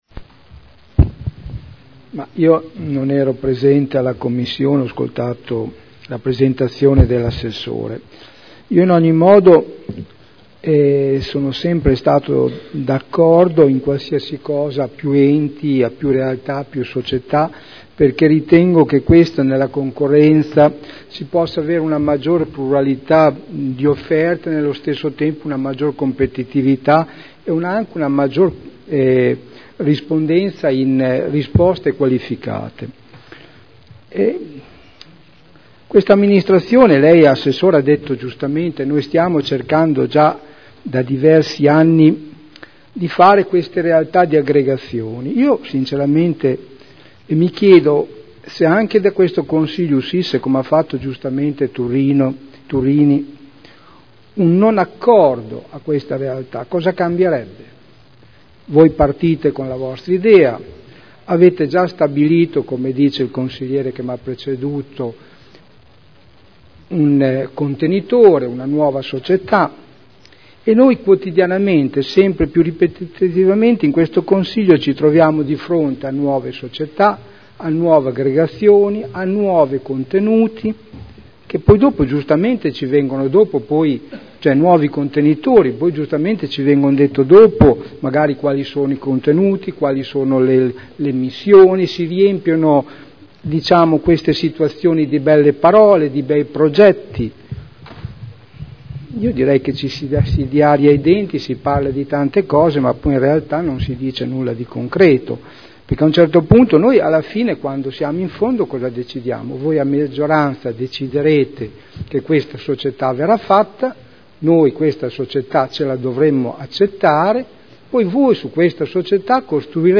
Seduta del 16 aprile. Proposta di deliberazione: Unificazione delle società pubbliche di formazione professionale dell’area modenese.